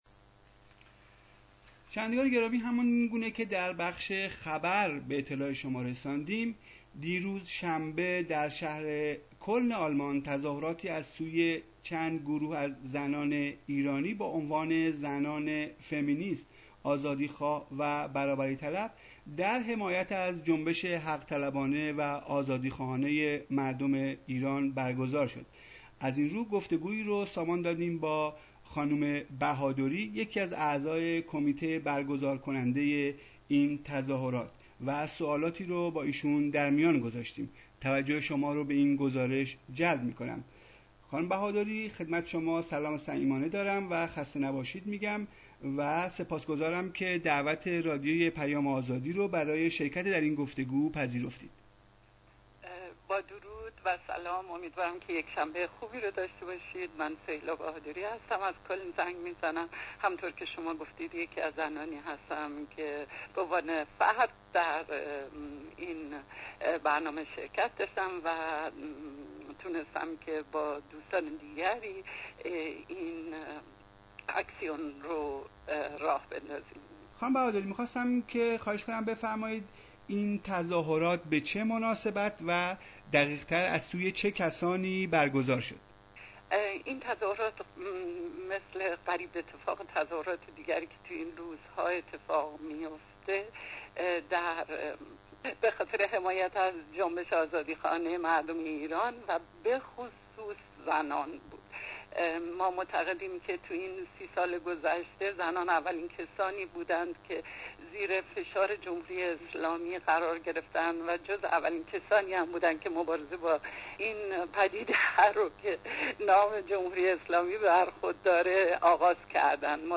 گزارش رادیو پیام آزادی از تظاهرات زنان فمنیست،آزادیخواه.برابری طلب در شهر کلن آلمان
gozaresh-az-koln.mp3